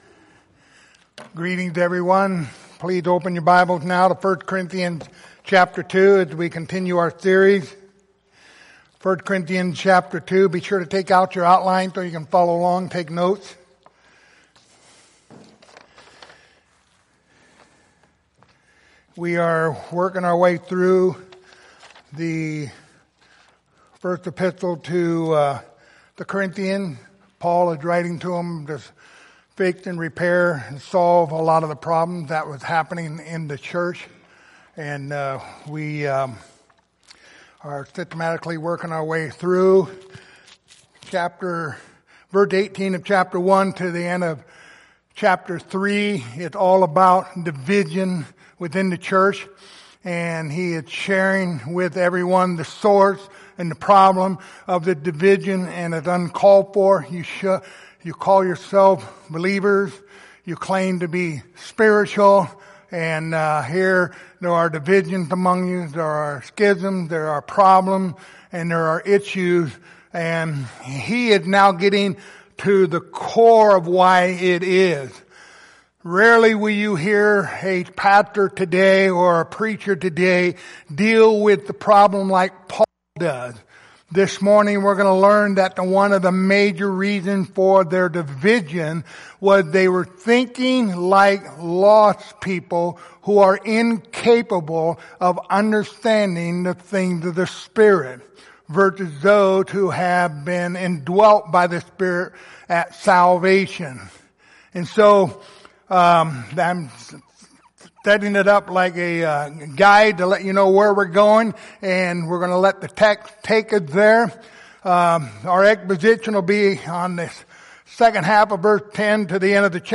Passage: 1 Corinthians 2:10-16 Service Type: Sunday Morning